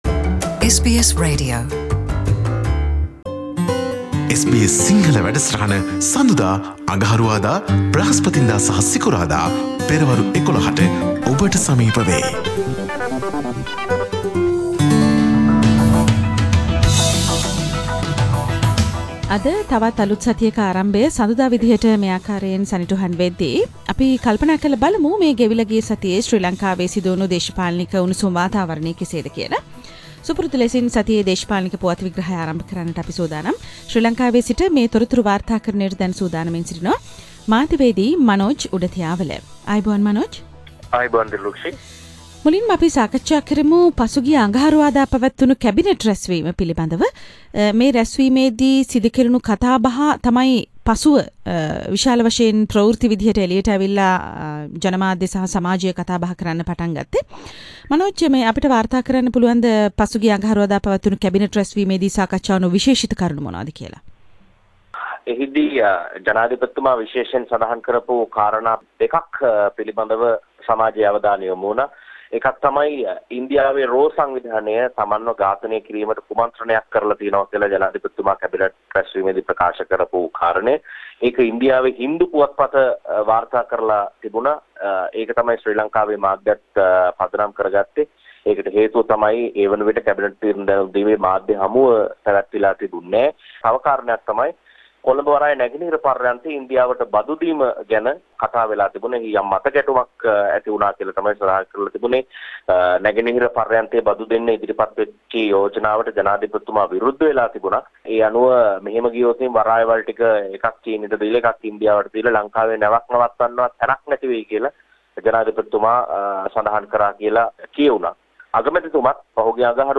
පුවත් සමාලෝචනය